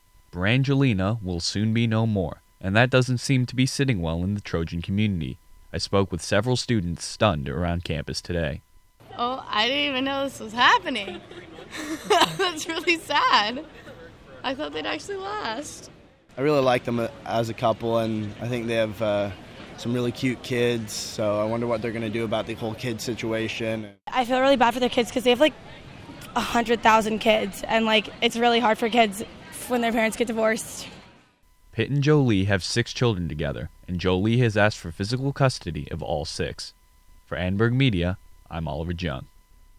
brangelina-voxpop-final_mixdown.wav